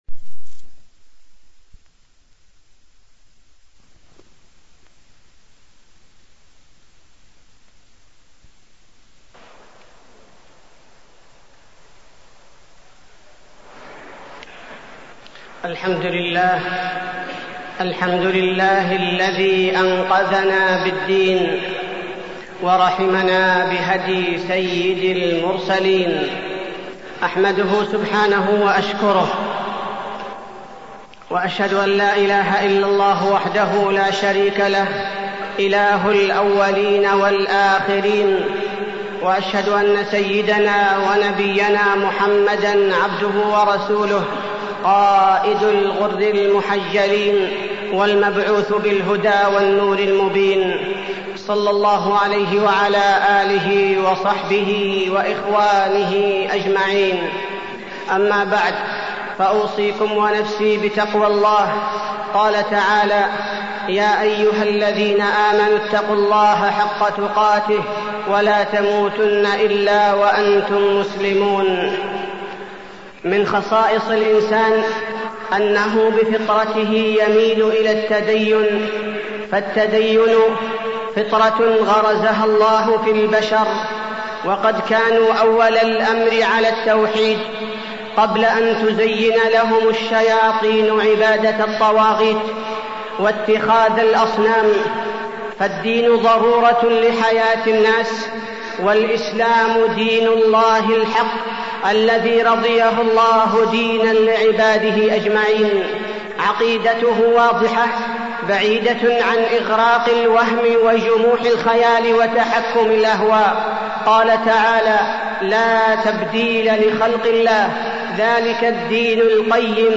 تاريخ النشر ١٨ شوال ١٤٢٤ هـ المكان: المسجد النبوي الشيخ: فضيلة الشيخ عبدالباري الثبيتي فضيلة الشيخ عبدالباري الثبيتي أثر التدين